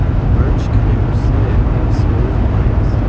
This algorithm aims to improve the intelligibility of a noisy speech signal without increasing the energy of the speech.
noisy_mixture.wav